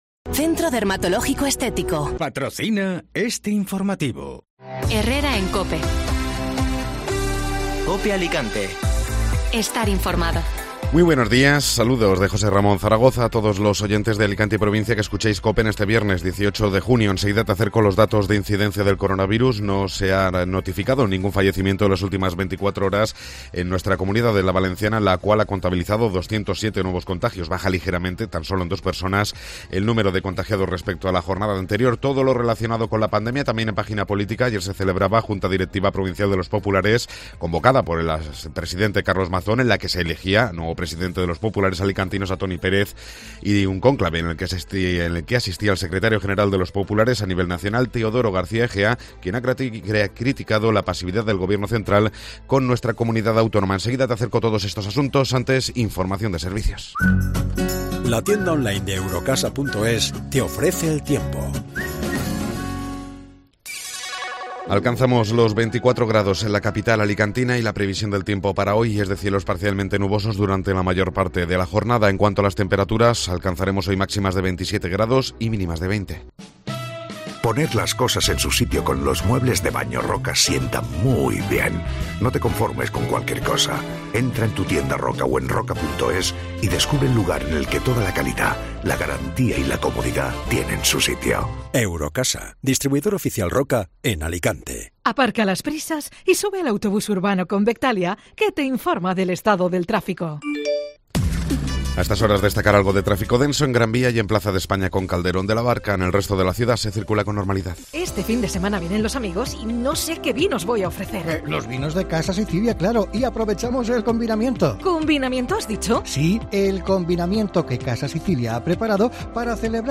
Informativo Matinal (Viernes 18 de Junio)